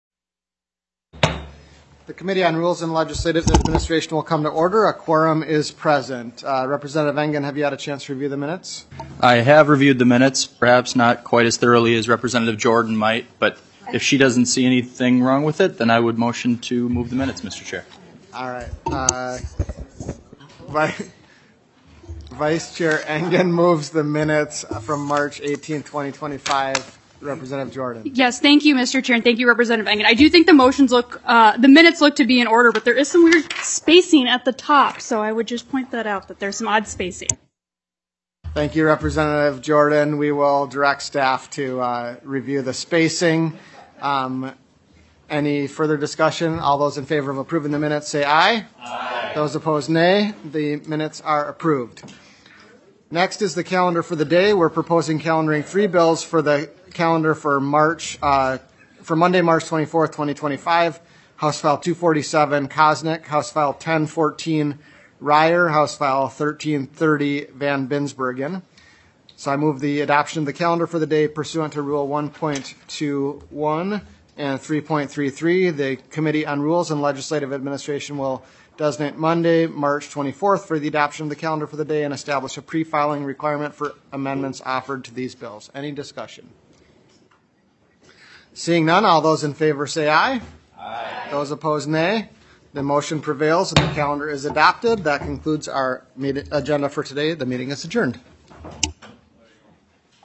Representative Niska, Co-Chair of the Rules and Legislative Administration Committee, called the meeting to order at 10:03 A.M. on March 20th, 2025, in Room G3 of the State Capitol.
Rep. Niska hold the gavel for this hearing.